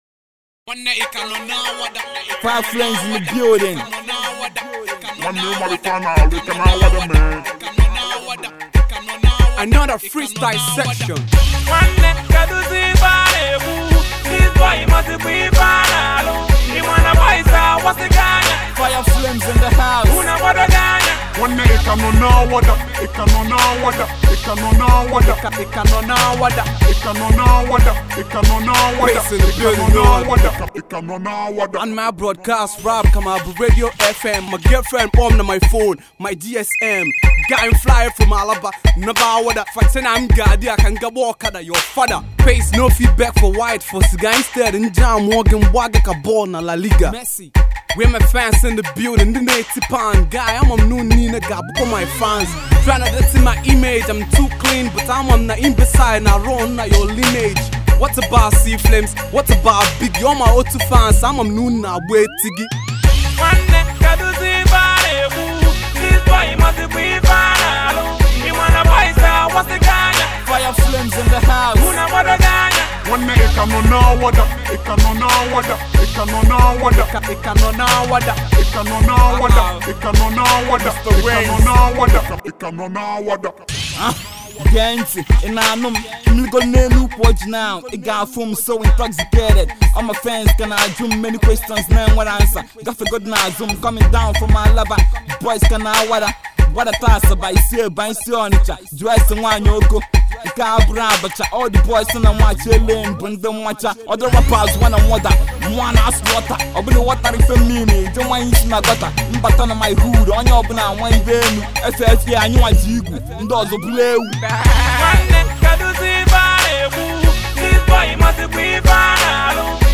Igbo Music, indigenous Hip-Hop
Rap Track